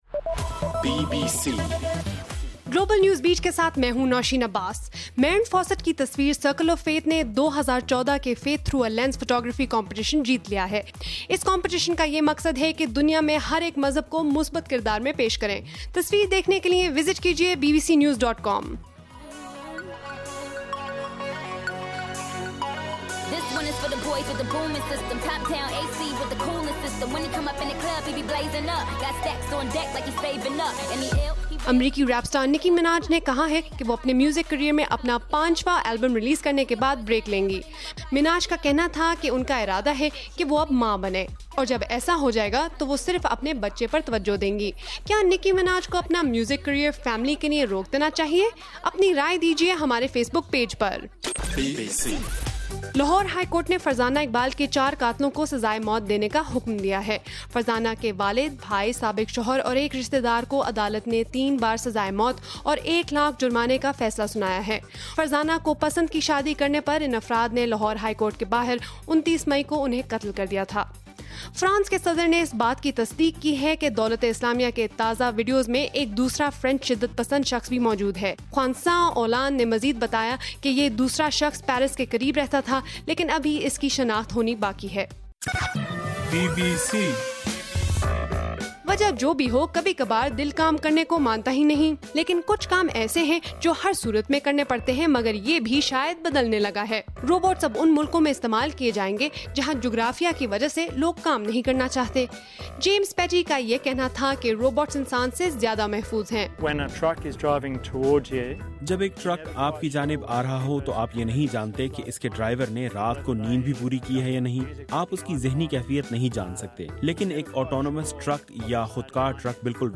نومبر 19: رات 9 بجے کا گلوبل نیوز بیٹ بُلیٹن